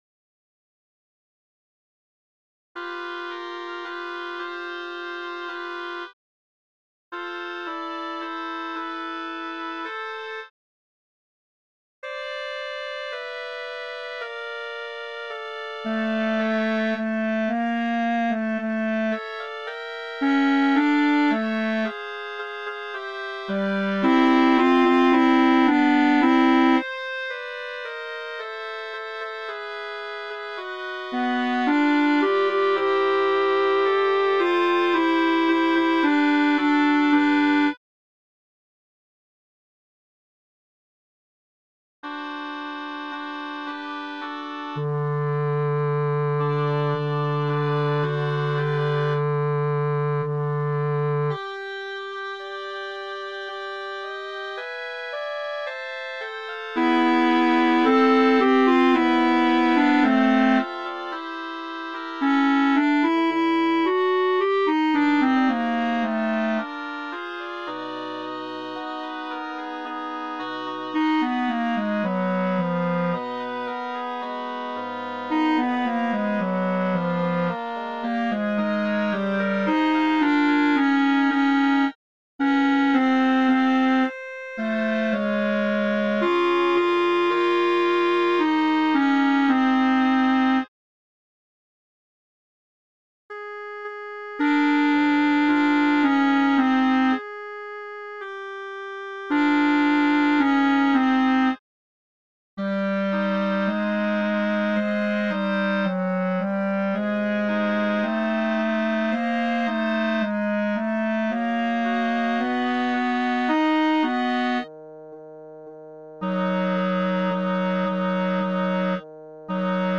Benedictus à 4 voix mp3
Les fichiers mp3 (de grandes dimensions) sont faits à partir des fichiers midi (de très petites dimensions), avec la voix sélectionnée accentuée.
Evidemment t2 signifie ténor 2